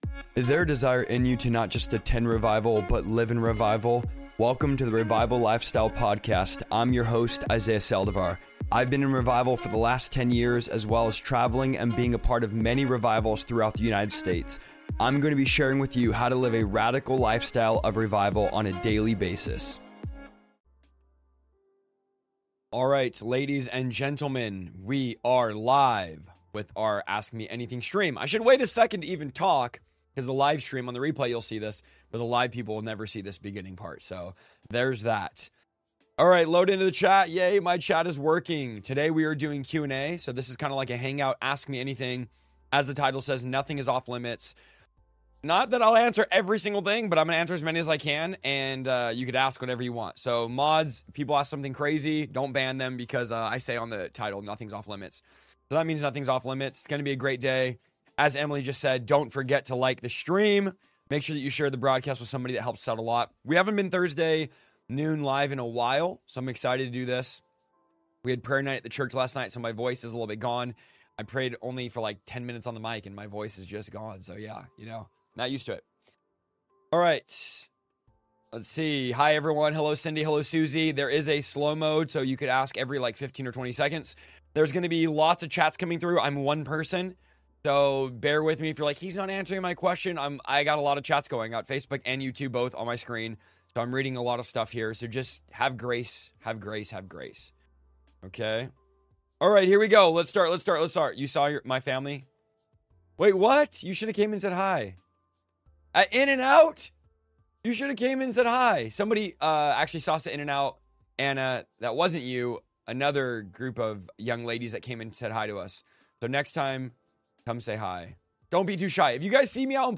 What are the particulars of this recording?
Ask Me Anything LIVE!